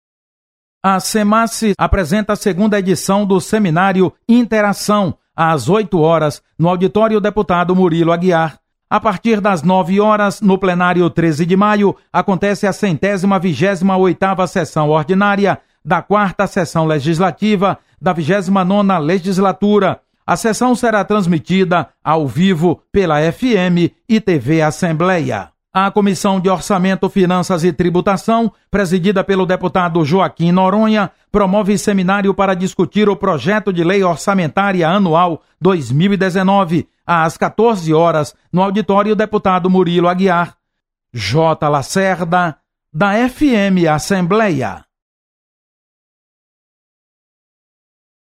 Acompanhe as atividades da Assembleia Legislativa nesta terça-feira. Repórter